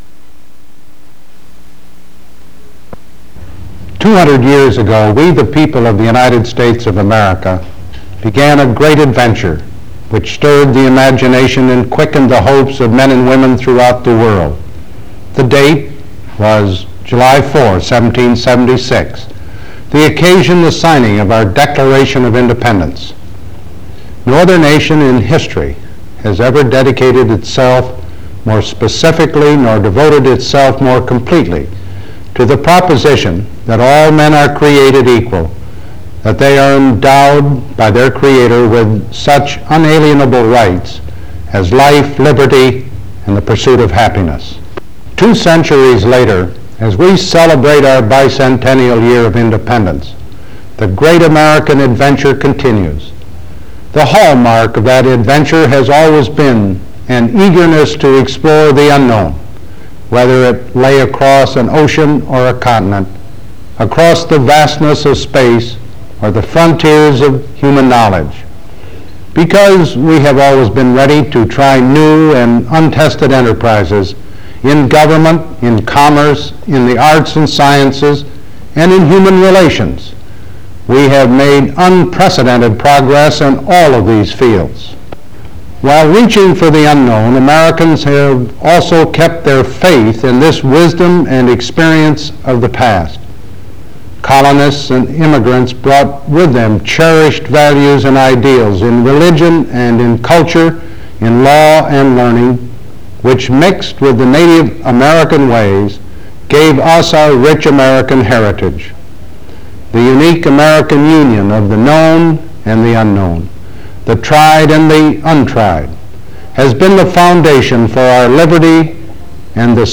President Ford speaks at Independence Hall in a ceremonial event to mark the nation’s Bicentennial.